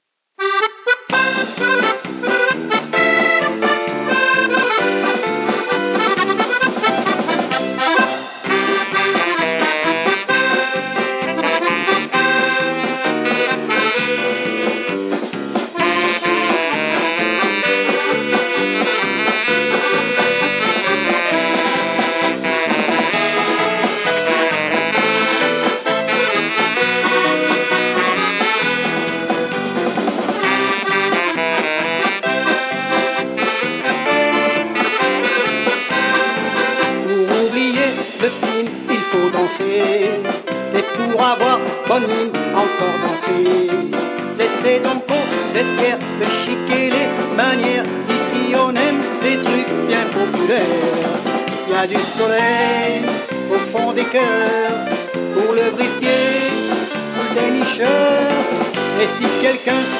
(Indicatif de début de bal)